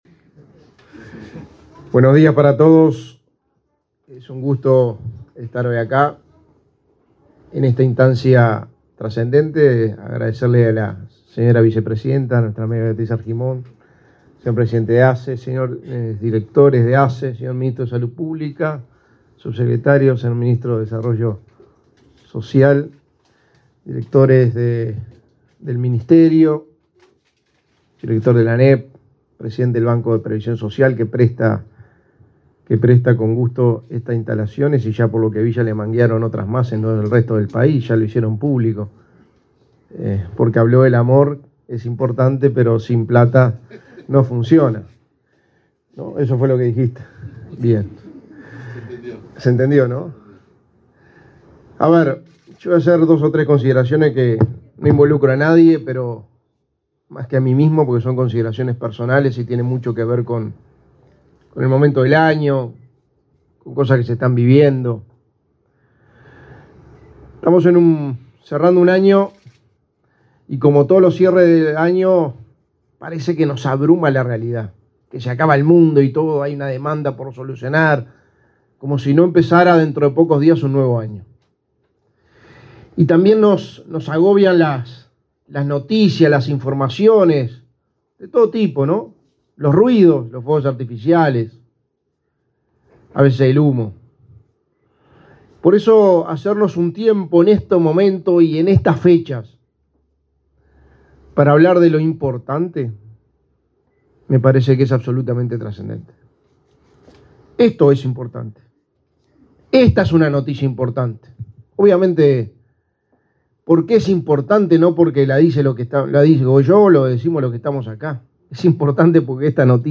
Palabra de autoridades en inauguración de ASSE en el Cerro de Montevideo 22/12/2022 Compartir Facebook X Copiar enlace WhatsApp LinkedIn El secretario de Presidencia, Álvaro Delgado; el ministro de Salud Pública, Daniel Salinas, y la vicepresidenta de la República, Beatriz Argimón, disertaron en el acto de inauguración de la Casa del Desarrollo de la Niñez, de la Administración de los Servicios de Salud del Estado, en el barrio Cerro, Montevideo.